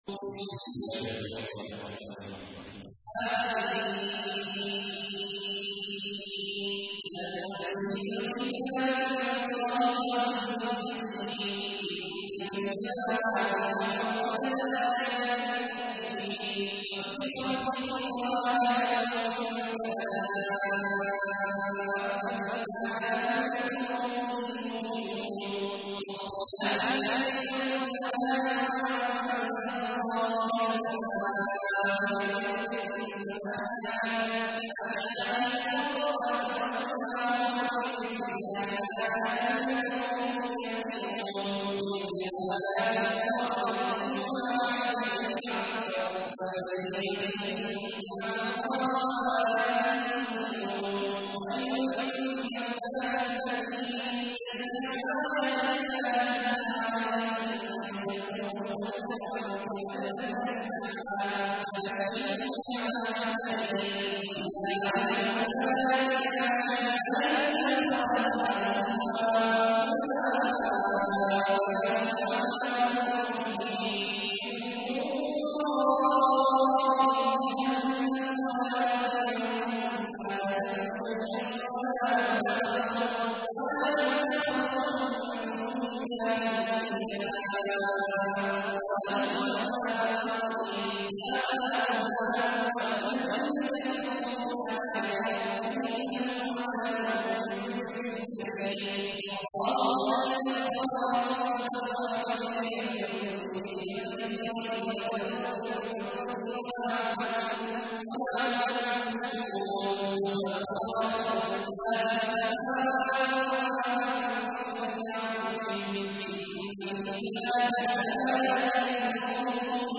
تحميل : 45. سورة الجاثية / القارئ عبد الله عواد الجهني / القرآن الكريم / موقع يا حسين